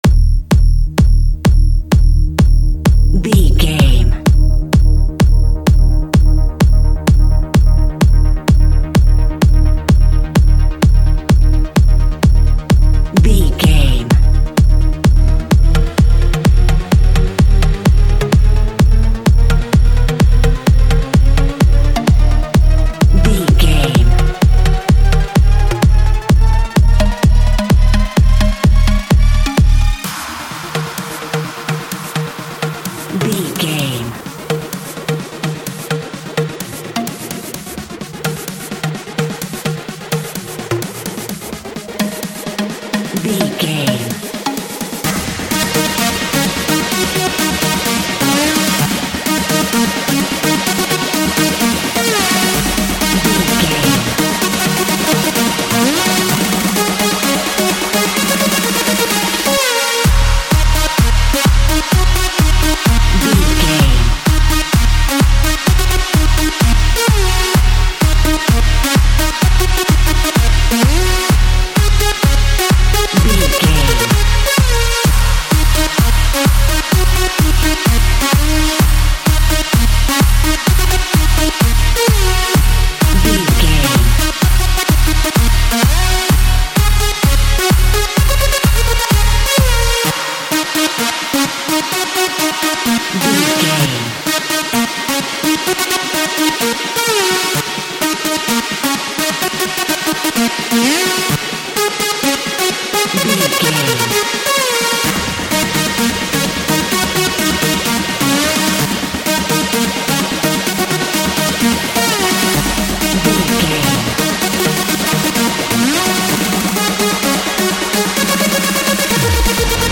Synthetic Trance .
Epic / Action
Fast paced
In-crescendo
Aeolian/Minor
E♭
aggressive
dark
groovy
driving
energetic
drums
synthesiser
drum machine
acid house
electronic
uptempo
synth leads
synth bass